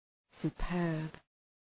{sʋ’pɜ:rb}